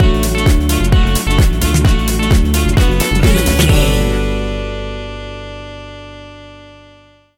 Sexy Funky Electro Music Stinger.
Aeolian/Minor
uplifting
energetic
bouncy
synthesiser
electric piano
bass guitar
saxophone
drum machine
groovy
upbeat